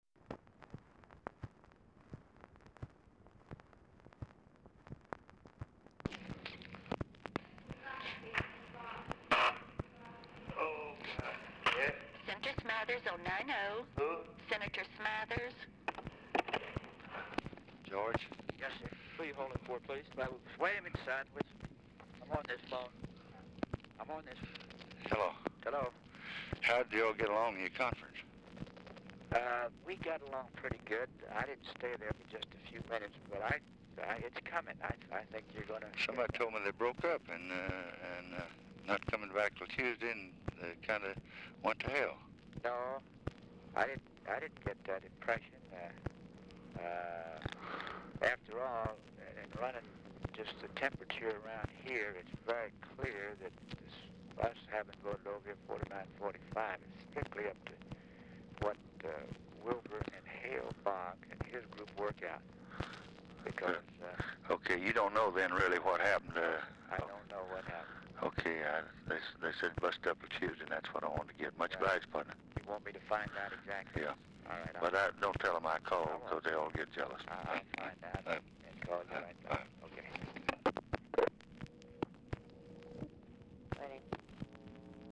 Telephone conversation # 5673, sound recording, LBJ and GEORGE SMATHERS, 9/24/1964, 5:20PM | Discover LBJ
Format Dictation belt
Location Of Speaker 1 Oval Office or unknown location
Specific Item Type Telephone conversation Subject Congressional Relations Health Policy Legislation Welfare And War On Poverty